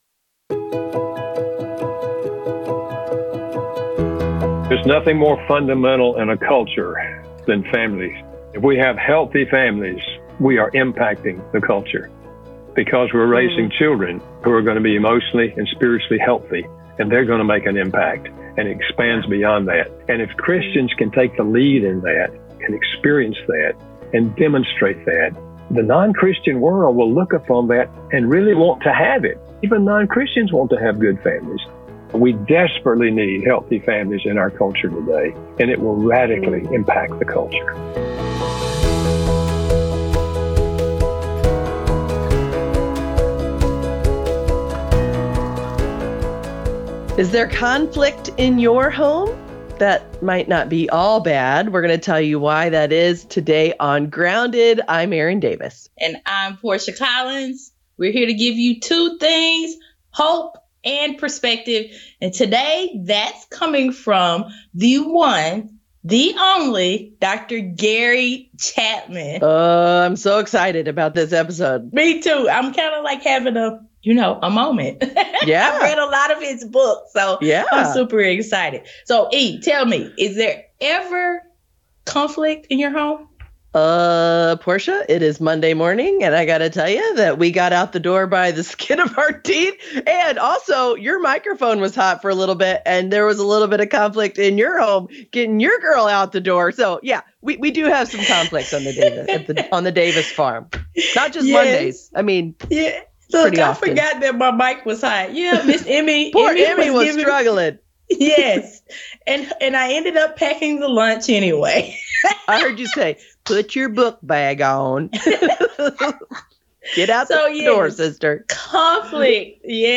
In this episode of Grounded, guest Dr. Gary Chapman joins the hosts to give a clear vision of what a healthy, thriving family should look like.